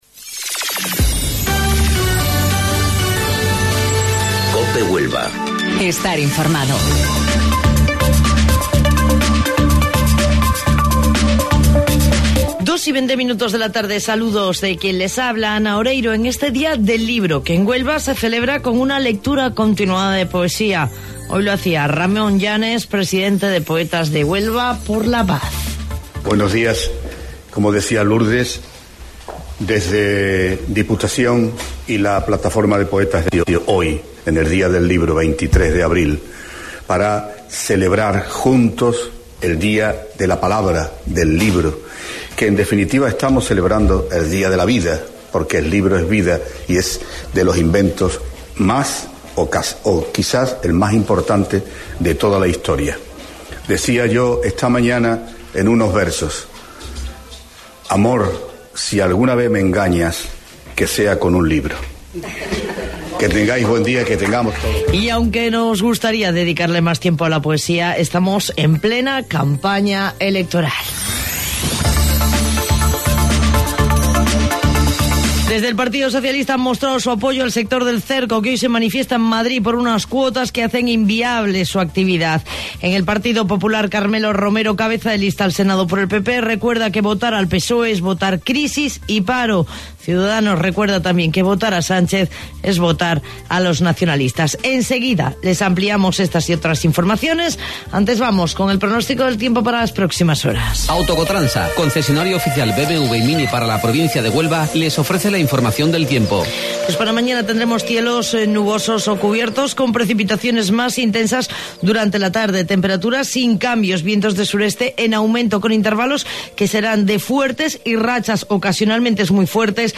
AUDIO: Informativo Local 14:20 del 23 de Abril